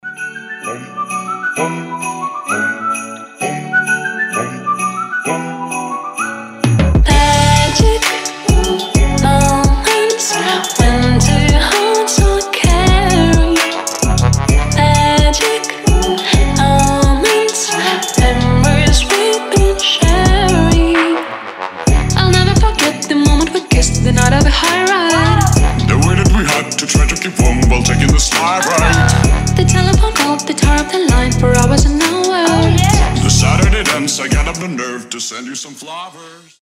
• Качество: 320, Stereo
свист
дуэт
Cover
красивый женский голос
колокольчики
рождественские